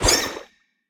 Sfx_creature_babypenguin_flinch_swim_03.ogg